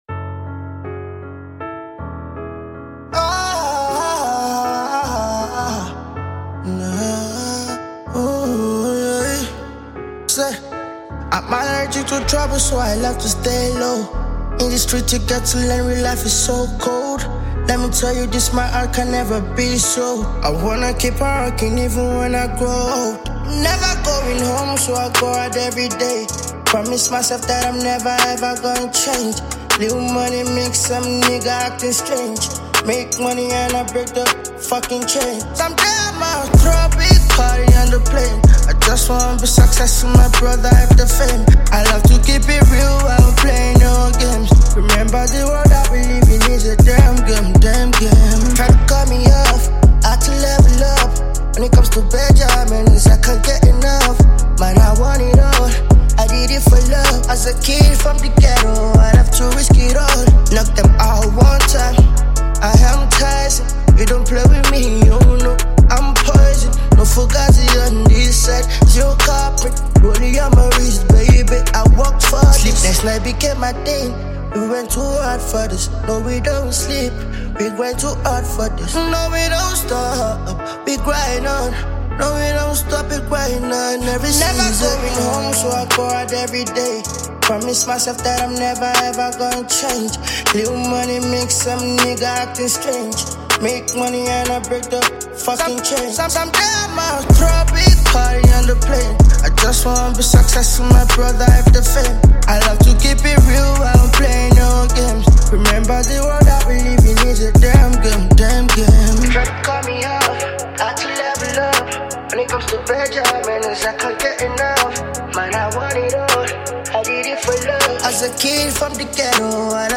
Nigerian talented singer